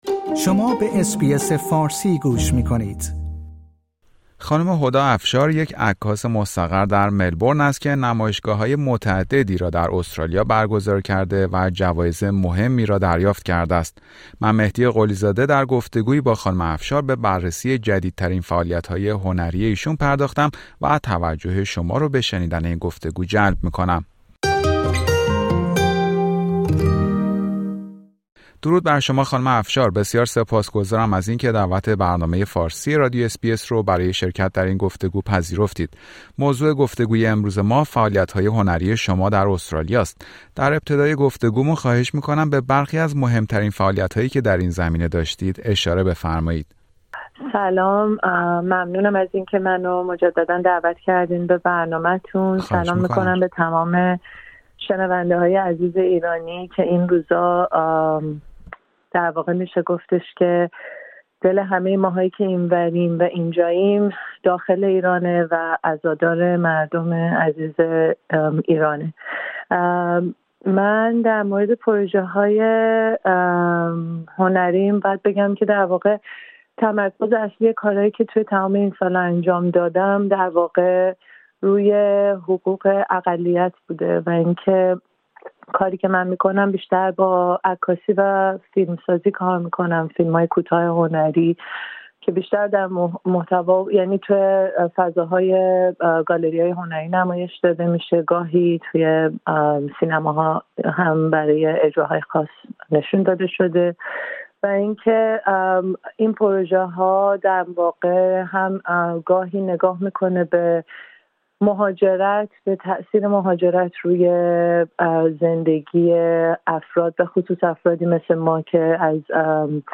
گفتگو با هنرمندی که برنده جوایز متعدد عکاسی در استرالیا شده است